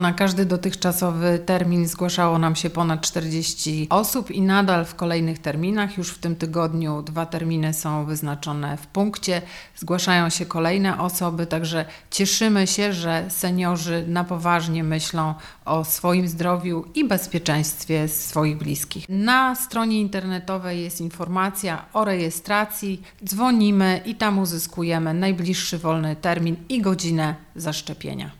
– Zainteresowanie akcją jest bardzo duże – powiedziała Natalia Walewska – Wojciechowska wiceprezydent miasta: